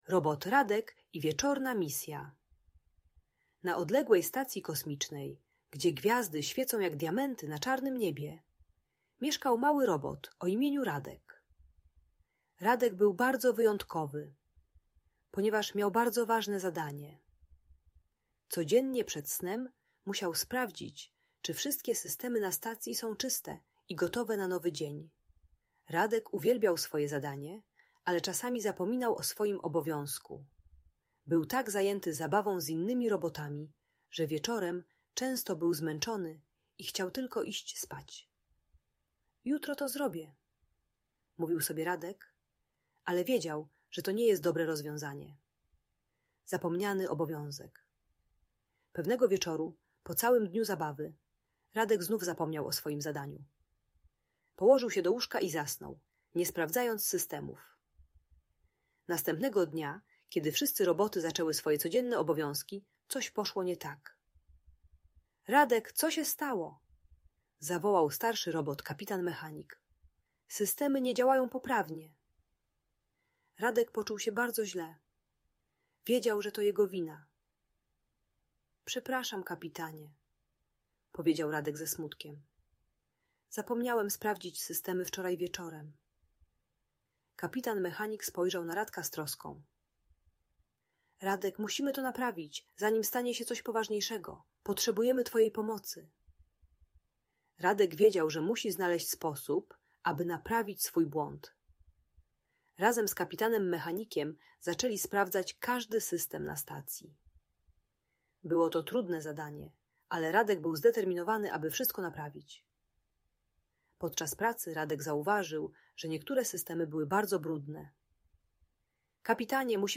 Robot Radek: Historia o odpowiedzialności - Trening czystosci | Audiobajka
Bajka dla dziecka które nie chce sikać przed snem i zapomina o toalecie. Audiobajka o treningu czystości dla dzieci 3-5 lat uczy wieczornego rytuału - korzystania z toalety przed snem.